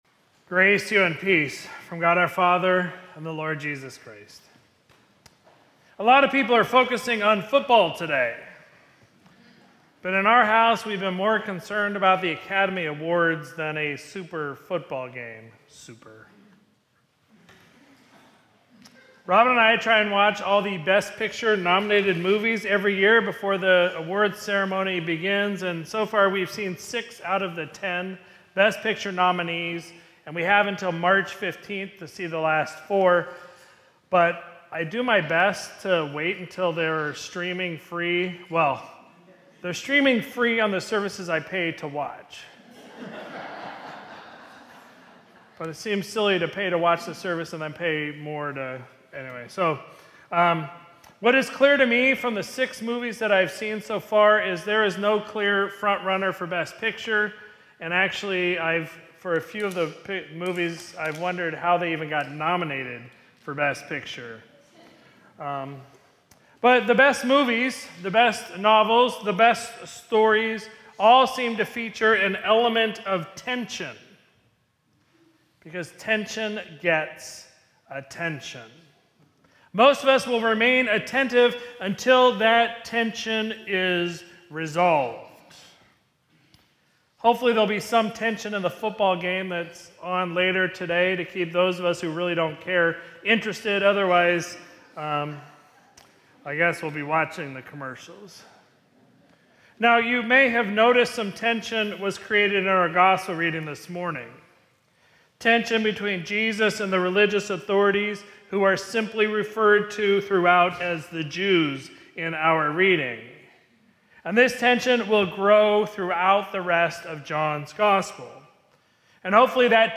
Sermon from Sunday, February 8, 2026